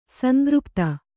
♪ sandřpta